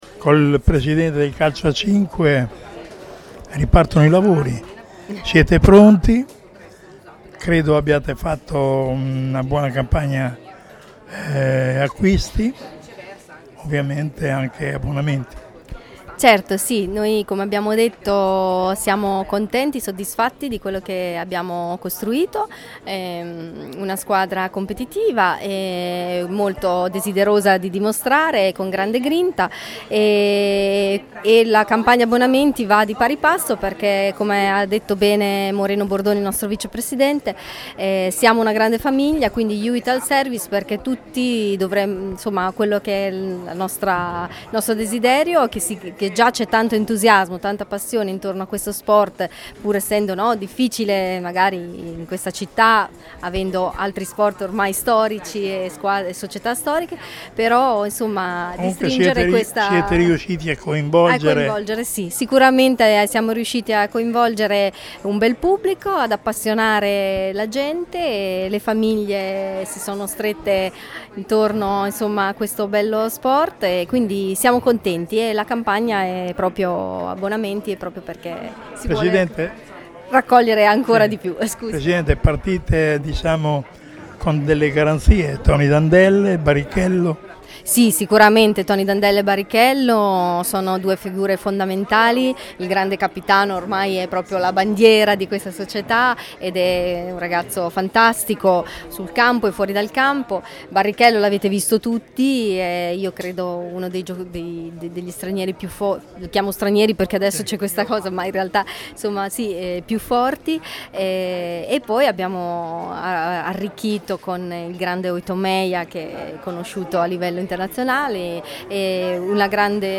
Le nostre interviste